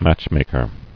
[match·mak·er]